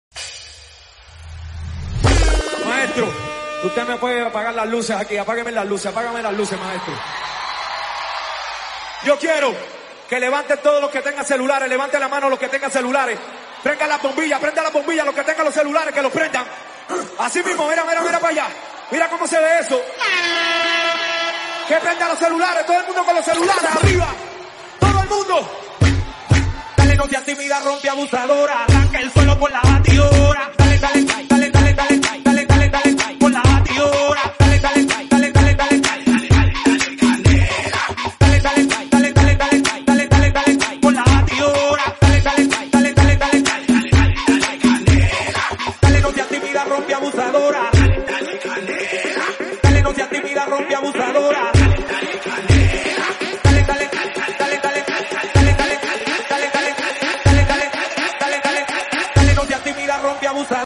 DanceHall Party Starter Remix - 126BPM